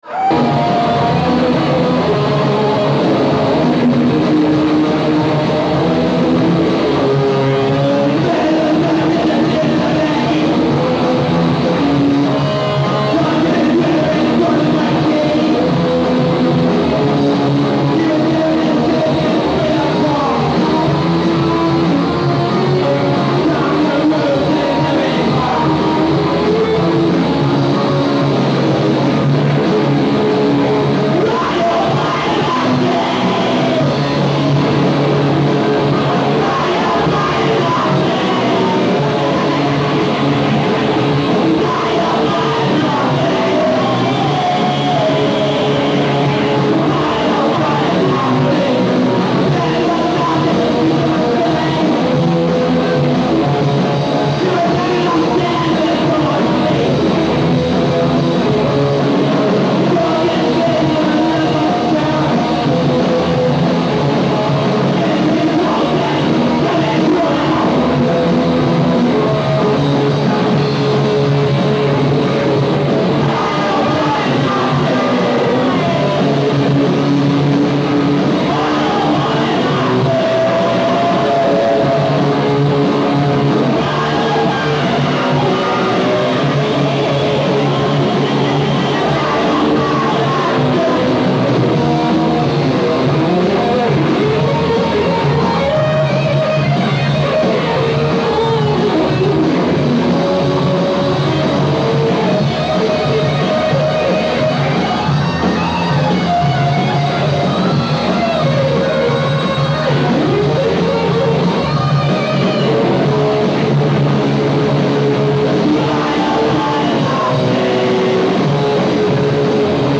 Video from CEC Philadelphia